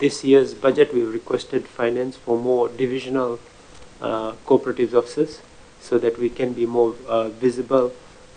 Permanent Secretary for Industry, Trade and Tourism Shaheen Ali, while presenting their 2018-2019 Annual Report to the Standing Committee on Economic Affairs, says three existing cooperatives were diversified into new activities.